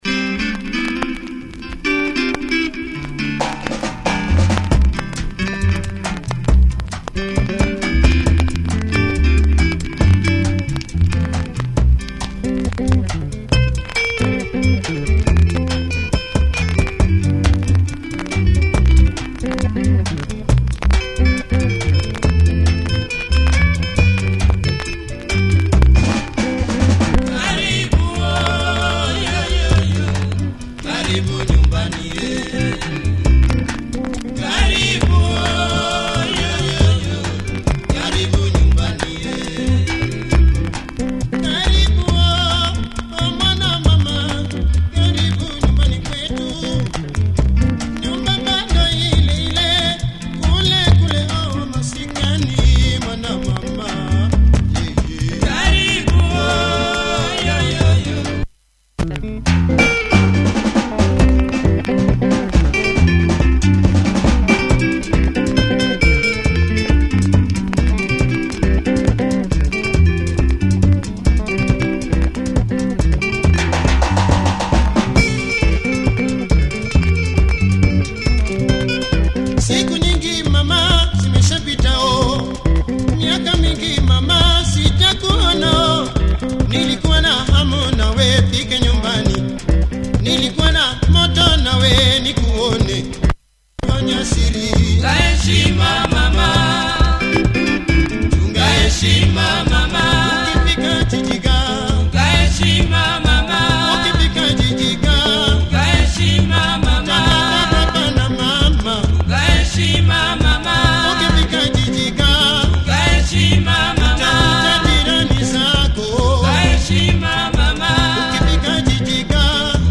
Nice lingala
with horns and driving beat!